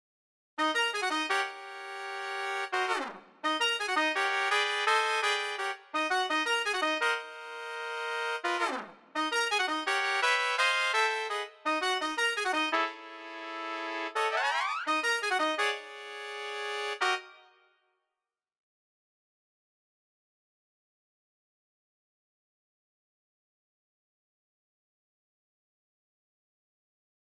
I ran this through Garritan JABB 3.
Only custom expression map stuff in this case…I added CCs to apply falls and doits for the trumpets. The rest of winds/brass just CC1 dynamics.
I am using a touch on the FX Send on Dorico’s Mixer from Reverence, the Large Stage preset with no further user tweaks.
Trumpets…